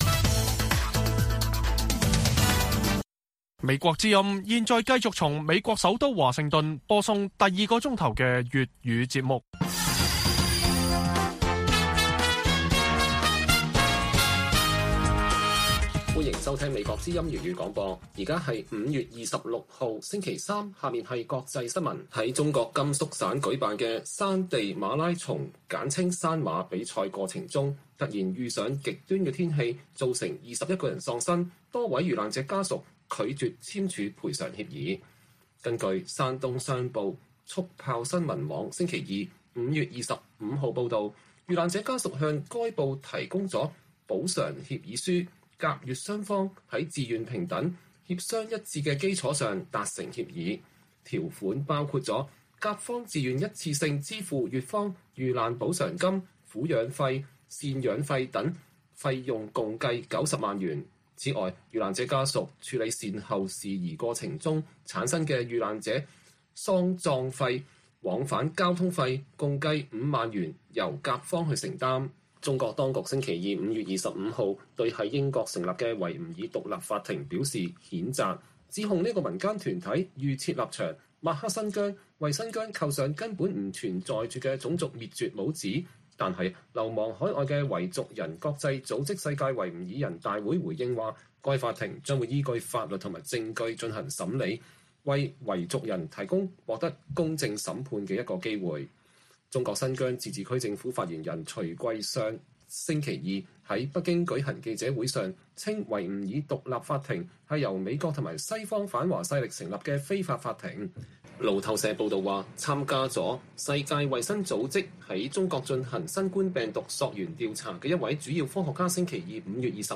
粵語新聞 晚上10-11點: 多位甘肅山地馬拉松遇難者家屬拒簽賠償協議